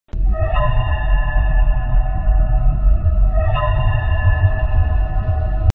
fnaf-2-hallway-noise_6mRuKPW.mp3